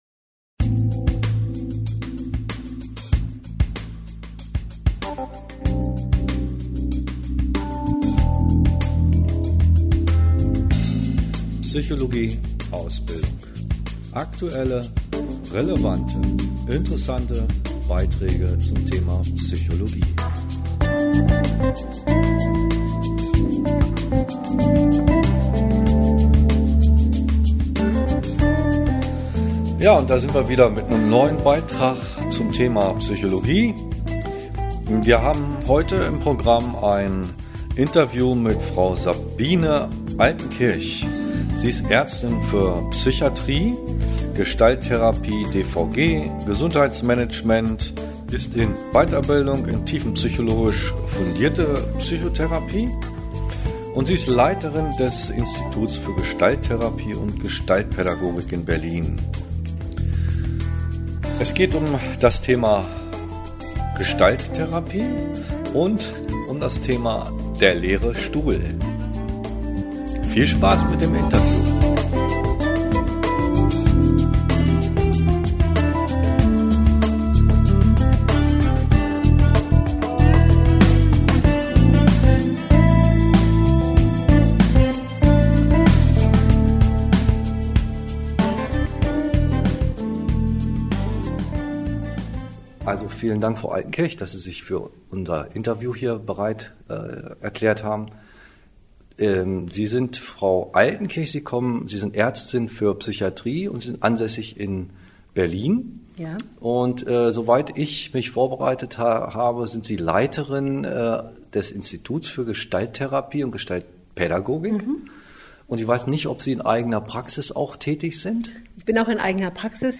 Podcast - Interview